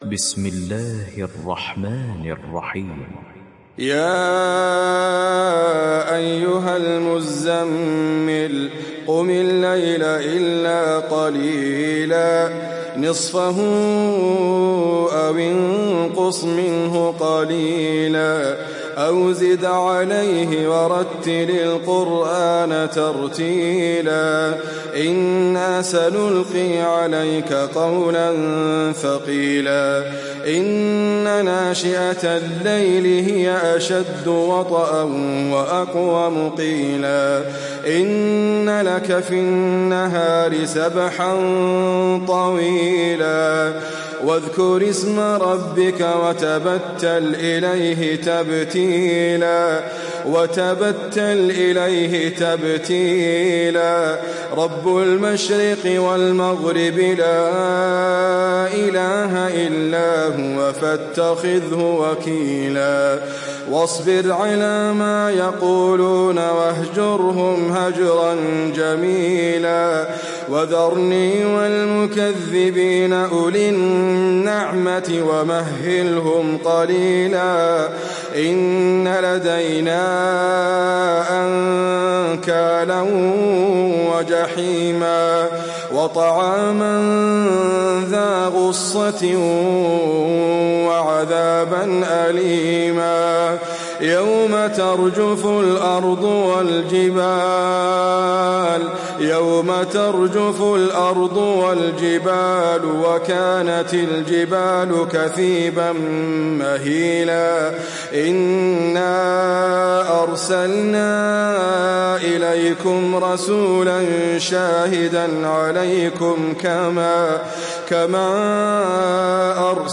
সূরা আল-মুযযাম্মিল ডাউনলোড mp3 Idriss Abkar উপন্যাস Hafs থেকে Asim, ডাউনলোড করুন এবং কুরআন শুনুন mp3 সম্পূর্ণ সরাসরি লিঙ্ক